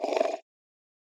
HippoSnores-004.wav